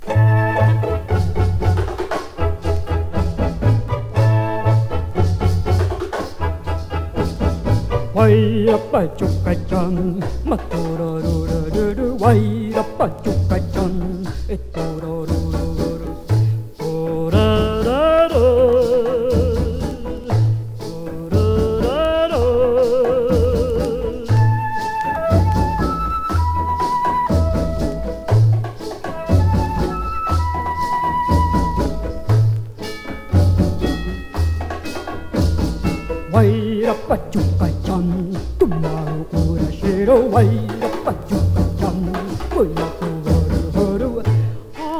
Jazz, Pop, Easy Listening　USA　12inchレコード　33rpm　Mono